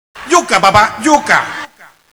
knife3.wav